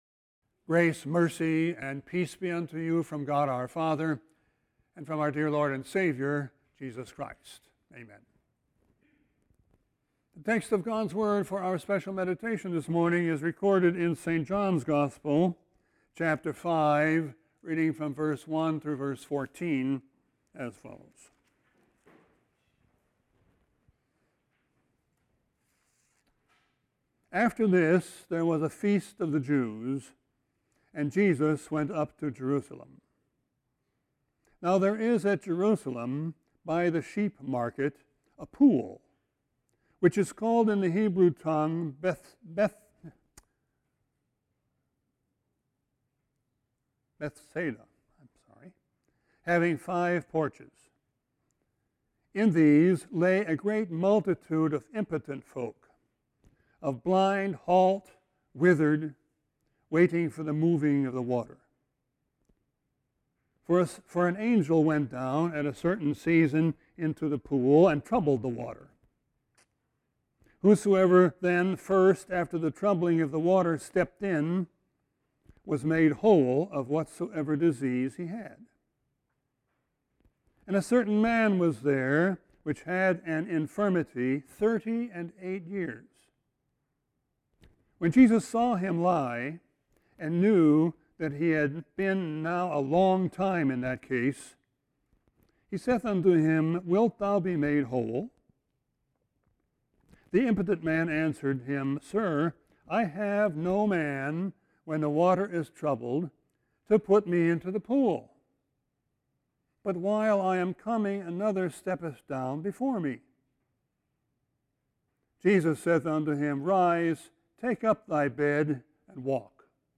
Sermon 9-5-21.mp3